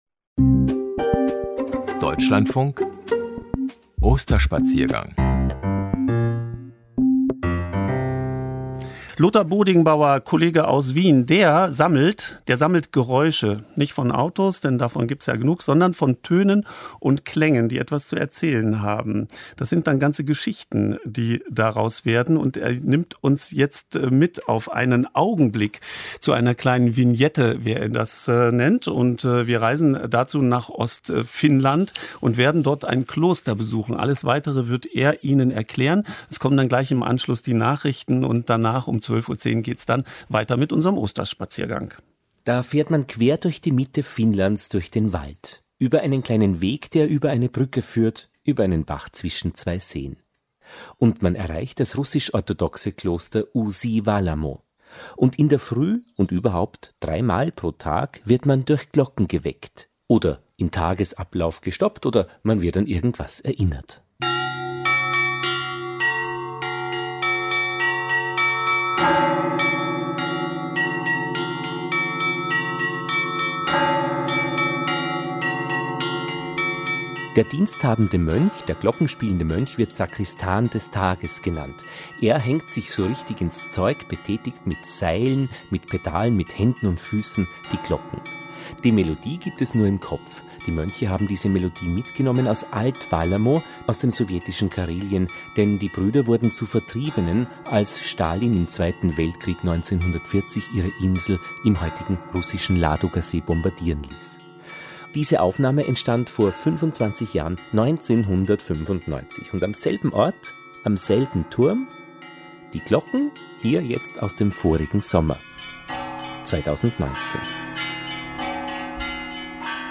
Klosterglocken Finnland